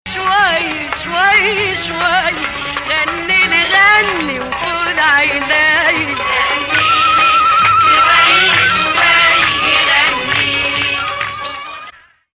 Rast 1